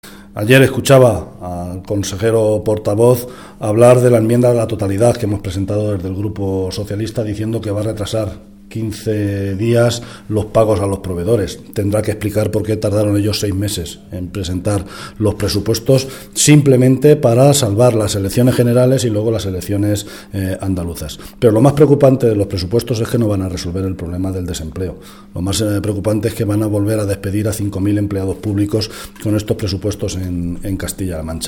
El portavoz del Grupo Parlamentario Socialista en las Cortes de Castilla-La Mancha, José Luis Martínez Guijarro, manifestó hoy en Cuenca que es el Gobierno de Cospedal el que tiene que explicar “por qué tardó seis meses en presentar los presupuestos”.
Cortes de audio de la rueda de prensa